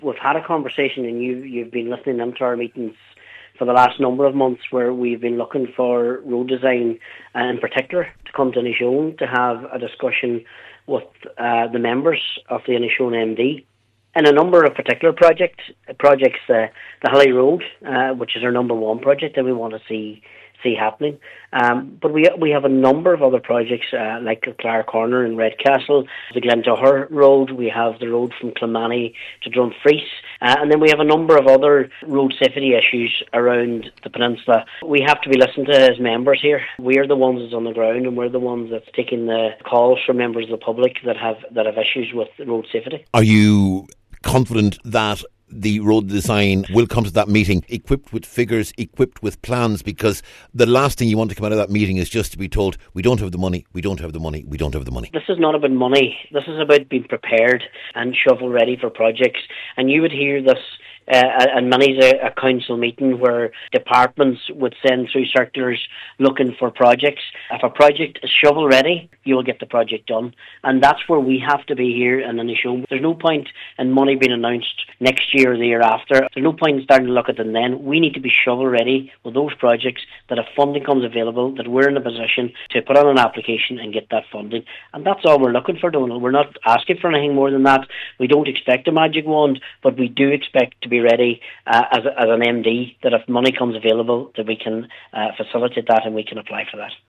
Cllr McDermott says it’s vital plans are in place for when funding becomes available.